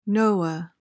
PRONUNCIATION: (NOH-uh) MEANING: adjective: Free from taboo, restrictions, etc. ETYMOLOGY: From Māori, Hawaiian, and Tahitian.